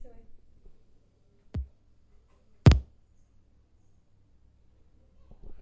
描述：打开电源时扬声器发出的声音。 于2017年8月使用Zoom H5录音机拍摄。
Tag: 扬声器上 扬声器接通 扬声器流行